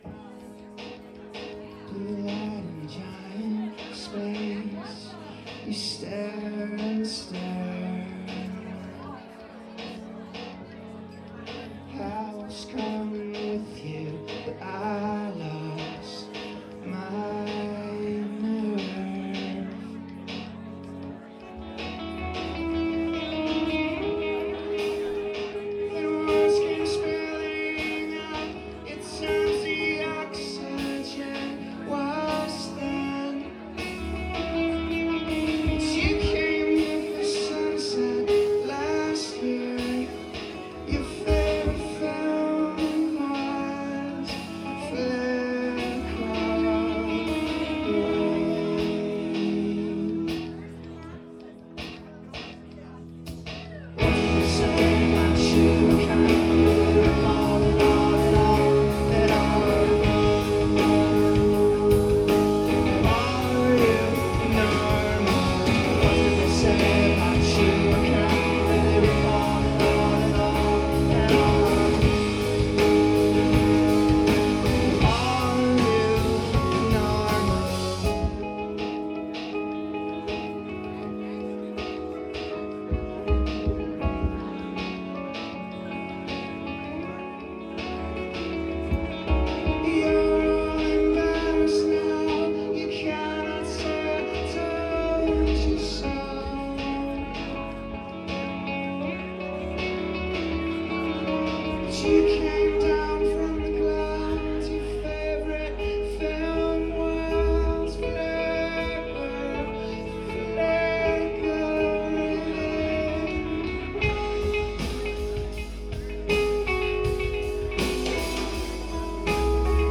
Sunset Tavern – Seattle, WA